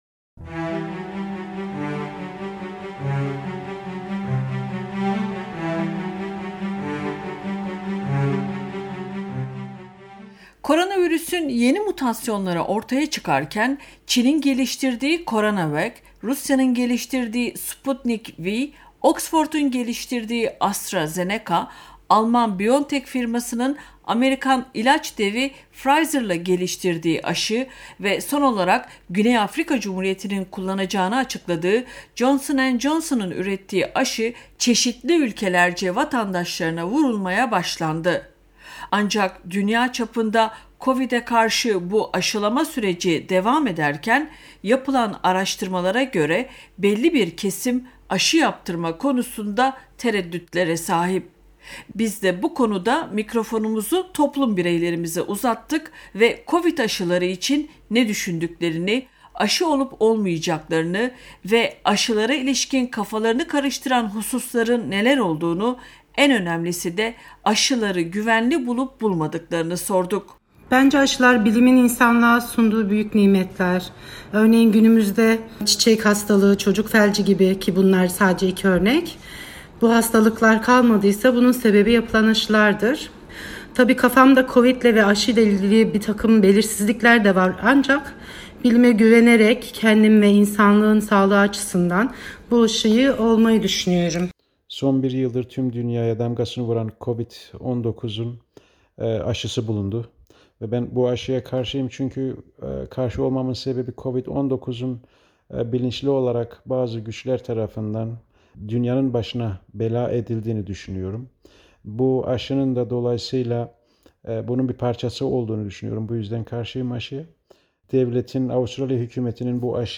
Biz de mikrofonumuzu toplum bireylerimize uzattık ve Covid aşıları için ne düşündüklerini, aşı olup olmayacaklarını ve aşılara ilişkin kafalarını karıştıran hususların neler olduğunu, en önemlisi de aşıları güvenli bulup bulmadıklarını sorduk.
covid_asilari_voxpop.mp3